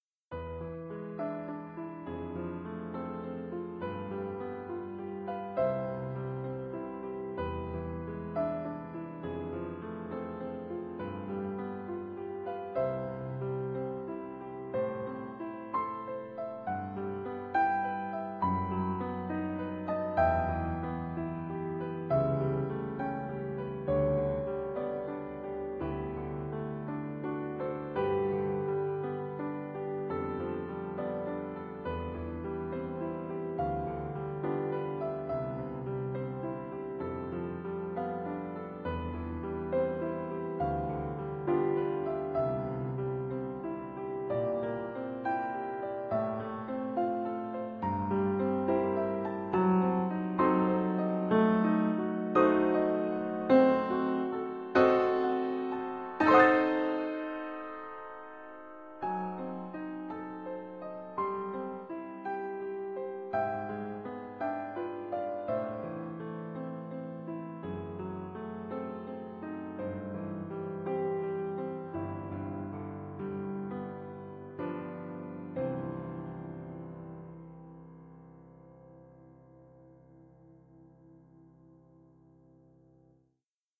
Sanft bewegt